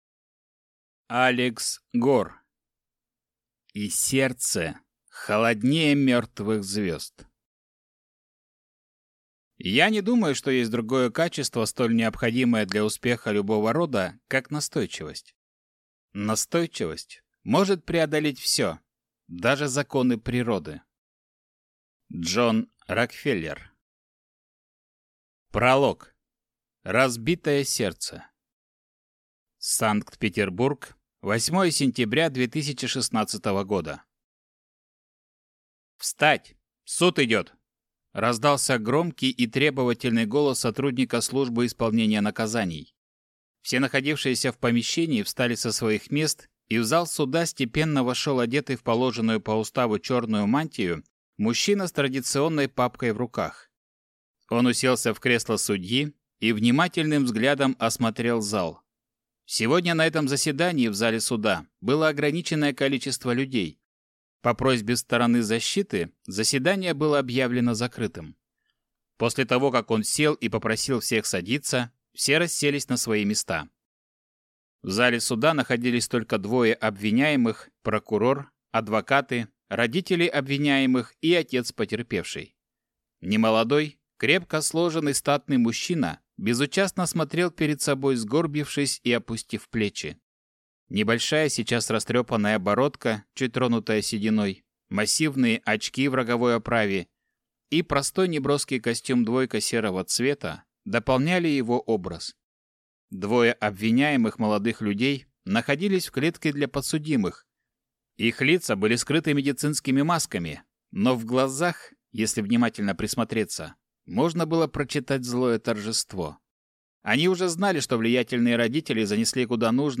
Аудиокнига И сердце холоднее мертвых звезд | Библиотека аудиокниг